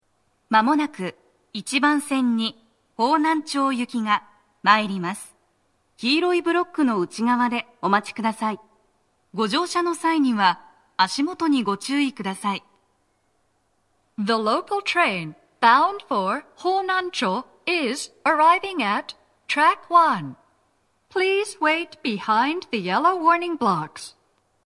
鳴動は、やや遅めです。
接近放送3
Panasonic天井型での収録です。